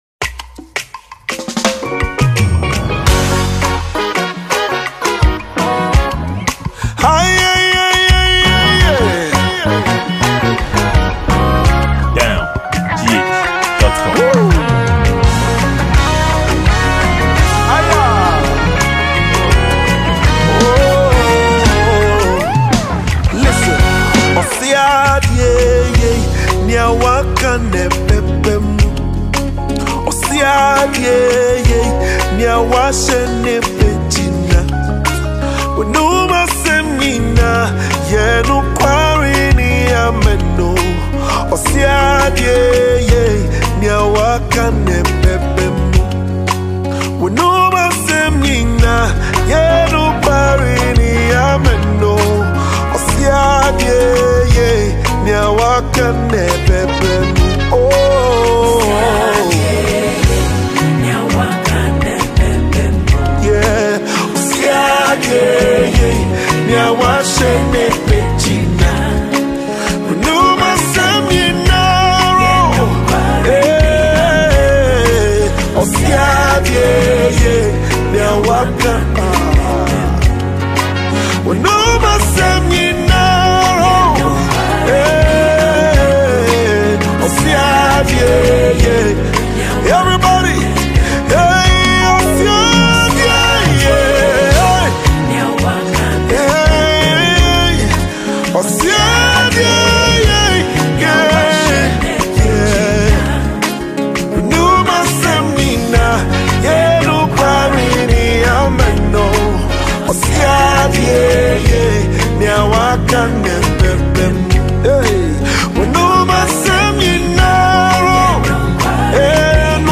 Ghanaian contemporary gospel musician